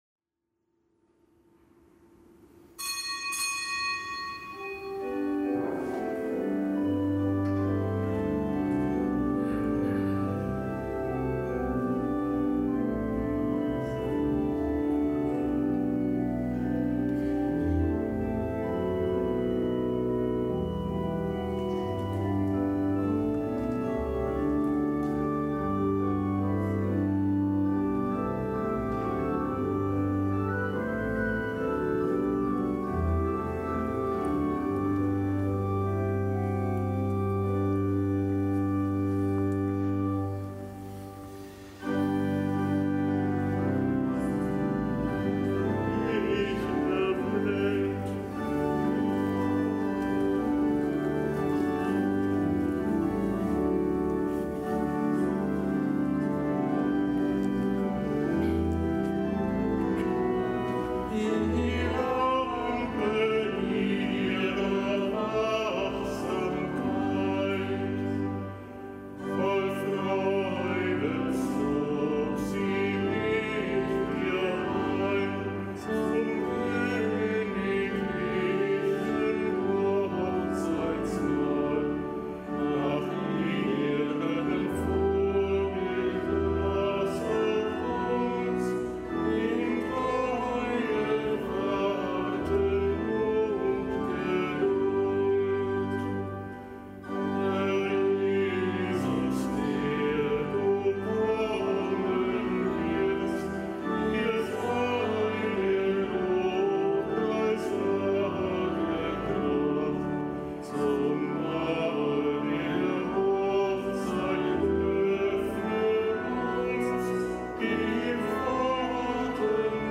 Kapitelsmesse am Gedenktag der Heiligen Klara von Assisi
Kapitelsmesse aus dem Kölner Dom am Gedenktag der Heiligen Klara von Assisi, einer Jungfrau und Ordensgründerin.